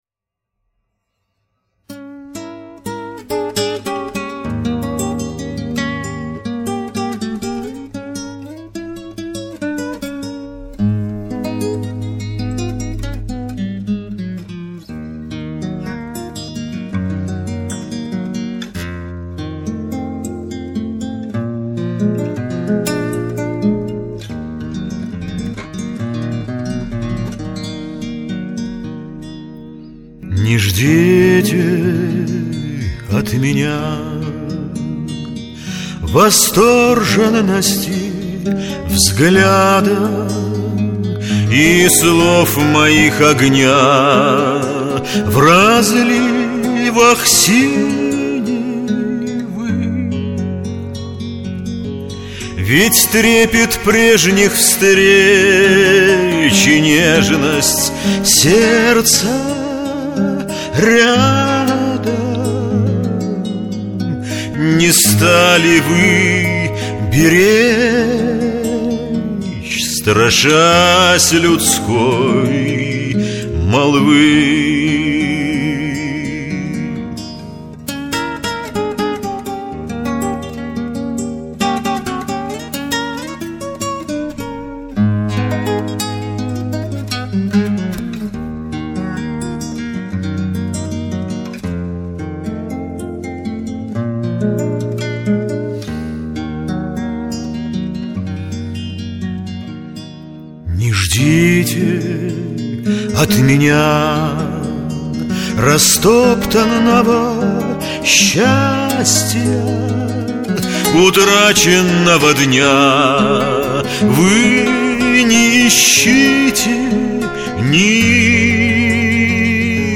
романс
Красиво, нежно, грустно...